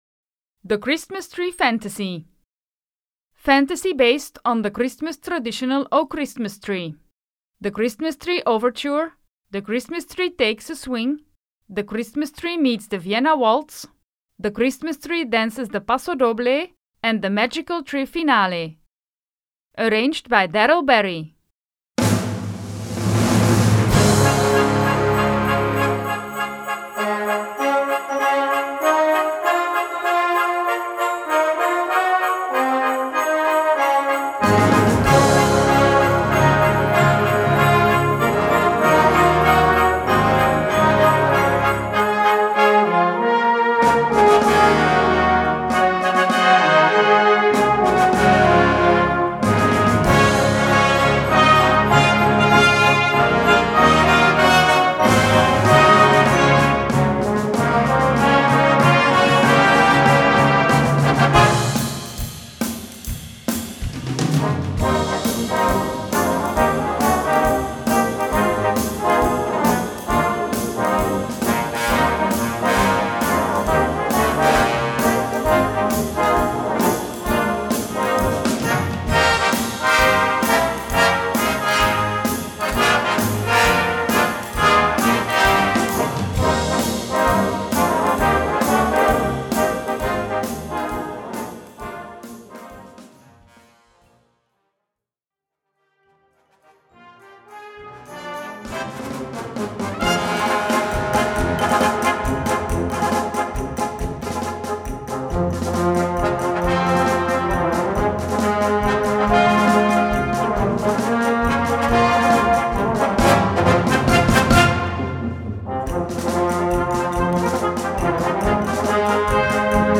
Gattung: Weihnachtsmedley
Besetzung: Blasorchester